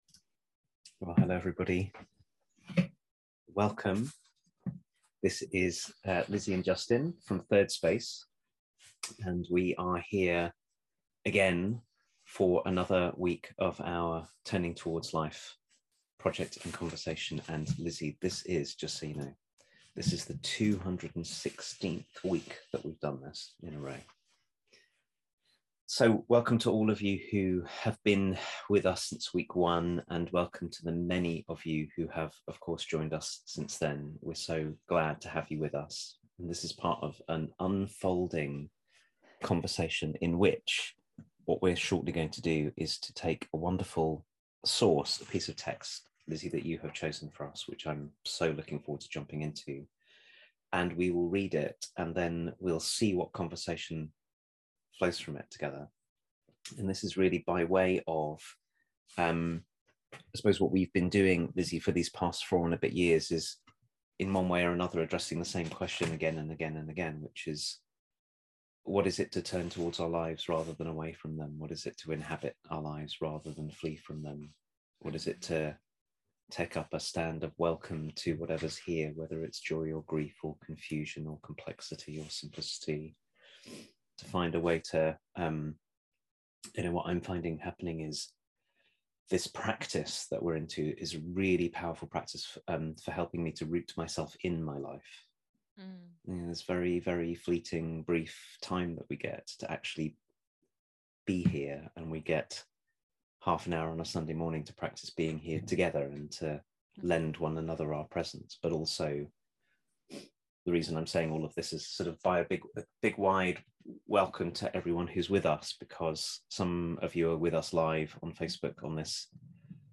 This week's Turning Towards Life is a conversation about how we might look anew, and in doing so breathe life back into places where we've allowed it to wither.